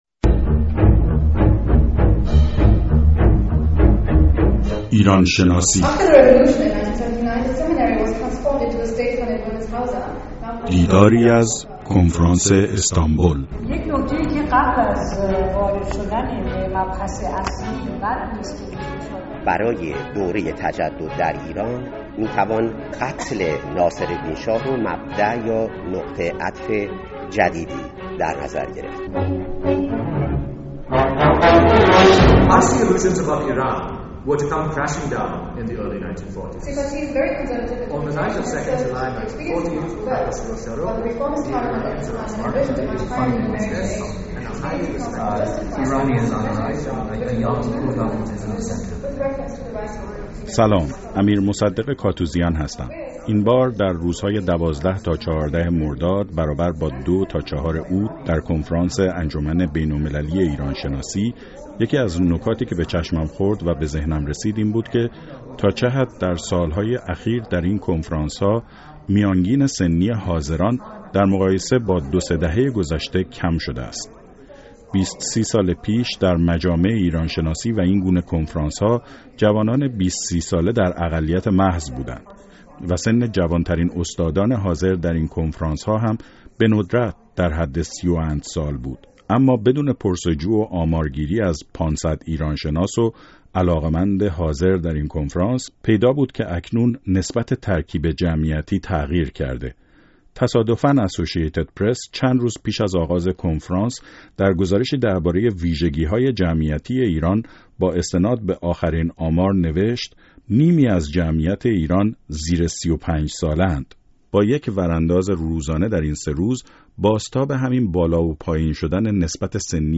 دیدار از کنفرانس استانبول (۳) گفت و گو با محقق جوان تاریخ